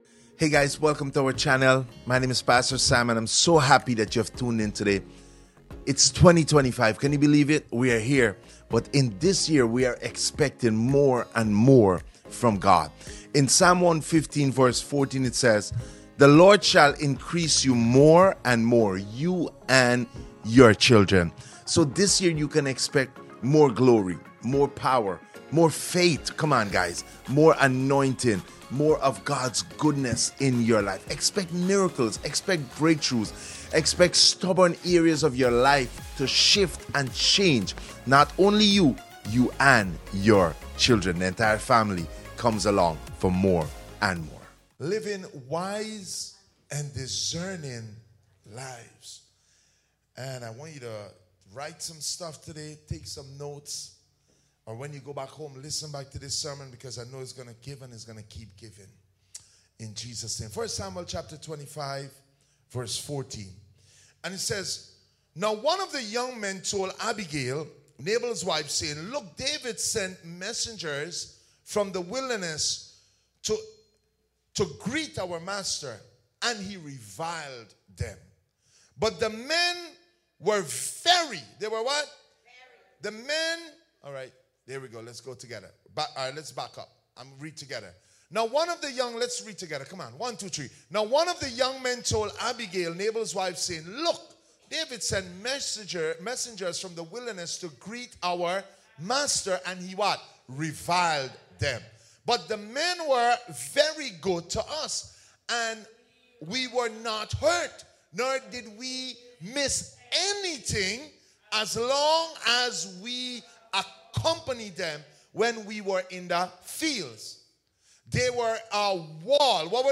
Sermons | Faith Church